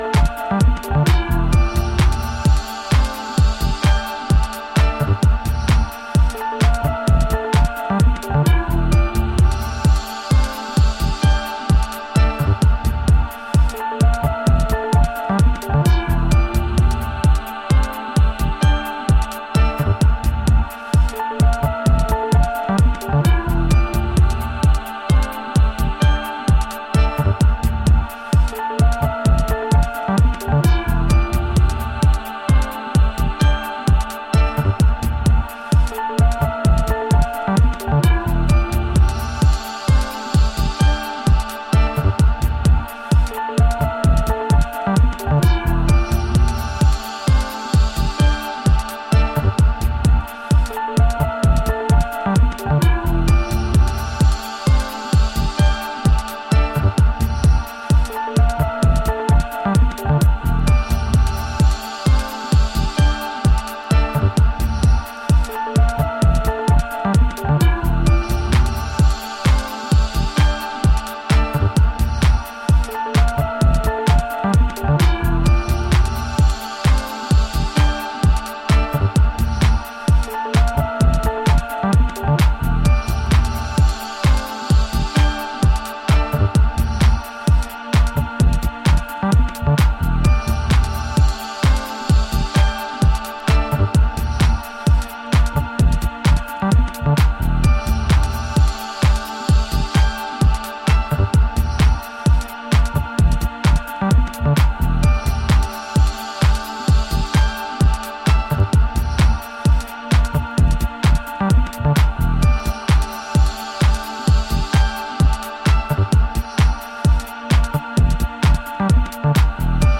a timeless mood inspiring subtle trip, cuts the air!